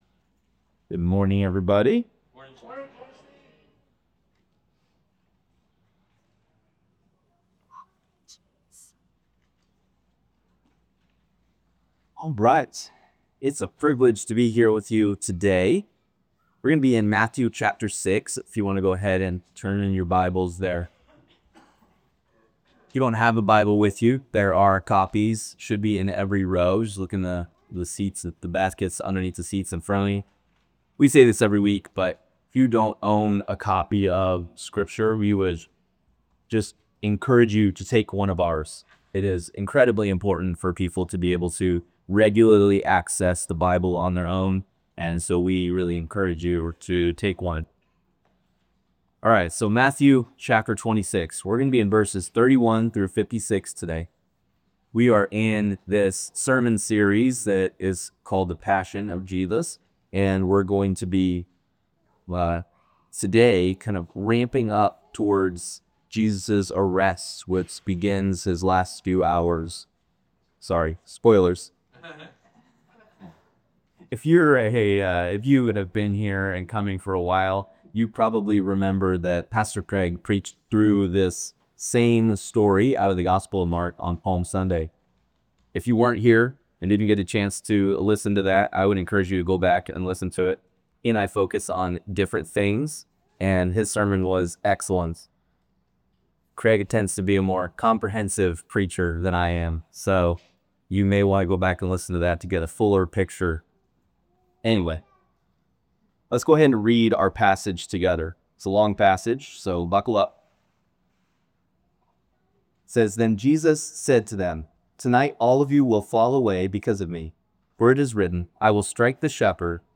Unfortunately we had some technical problems with our live stream this week so there is not video and our audio quality is not the best, but here is Sunday's sermon regardless. Join us this week as we explore the humanity of Jesus in this sermon from Matthew 26:31-56.